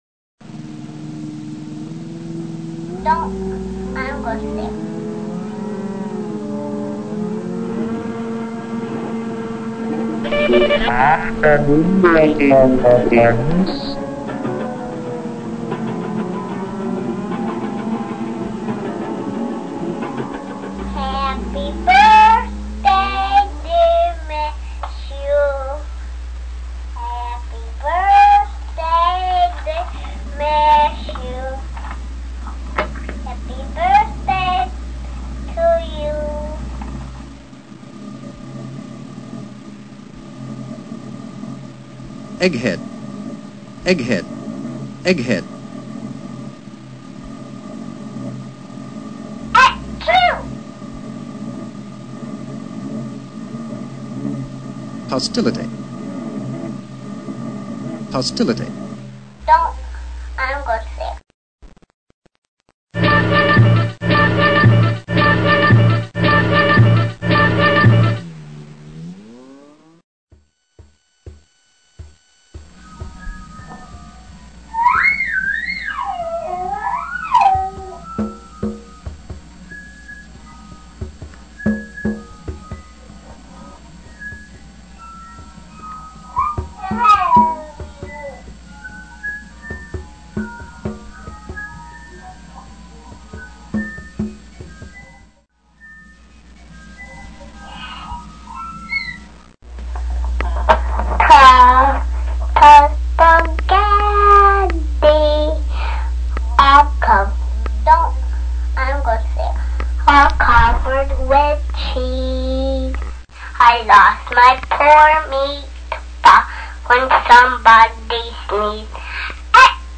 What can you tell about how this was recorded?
Excerpts from audio playing inside installation (768k mp3) Multimedia Installation - Troy Art Center - Spring 2001